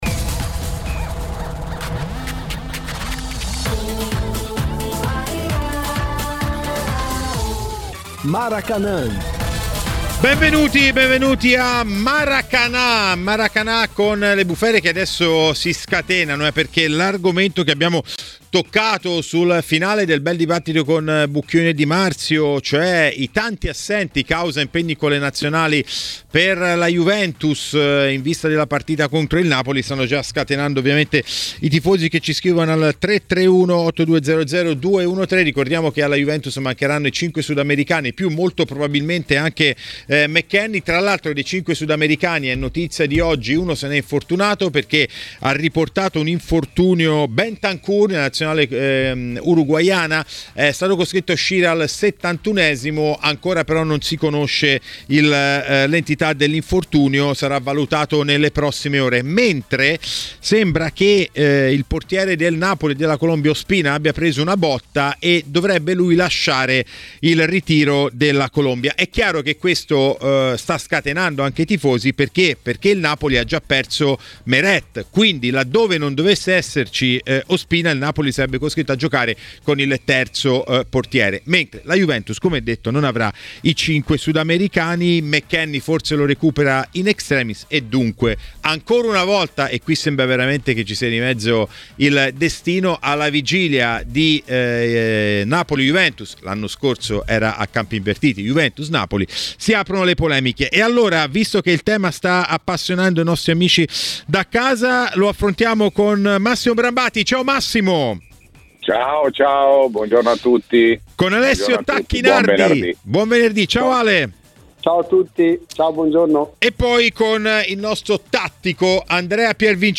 L'ex calciatore e tecnico Alessio Tacchinardi a TMW Radio, durante Maracanà, ha detto la sua sulla Juventus, di Nazionale e non solo.